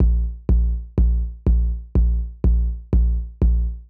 ENE Beat - Kicks.wav